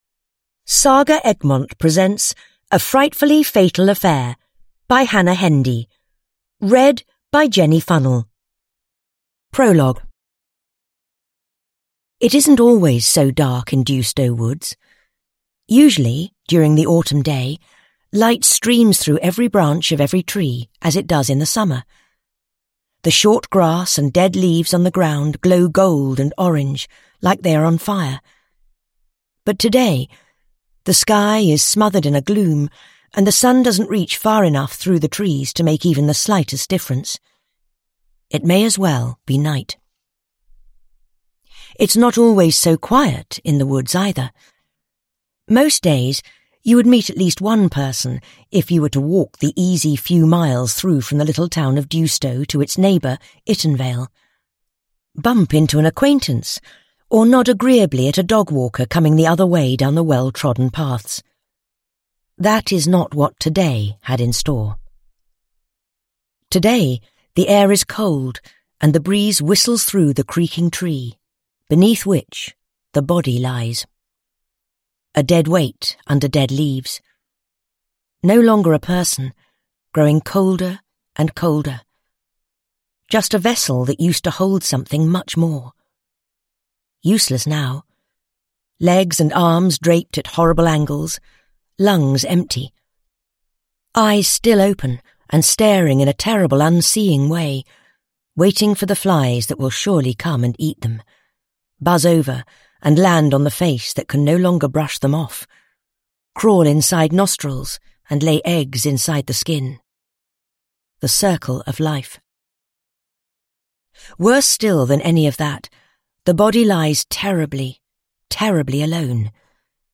A Frightfully Fatal Affair / Ljudbok